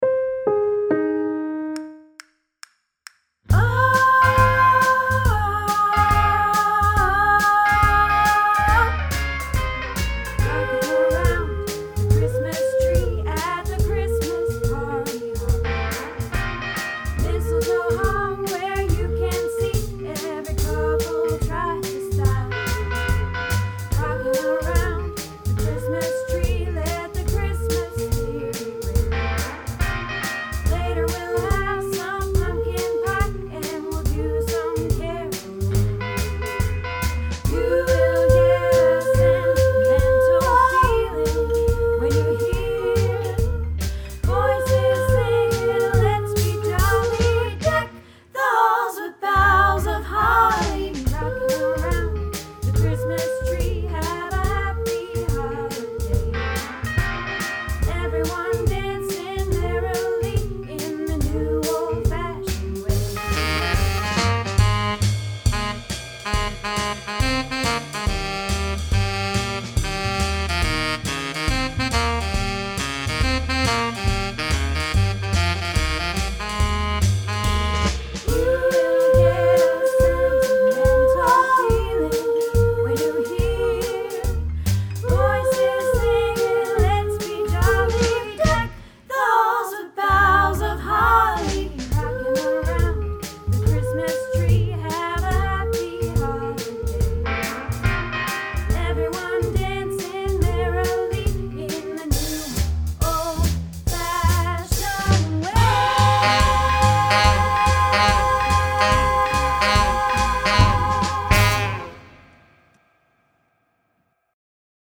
Rockin Around the Christmas Tree - Sop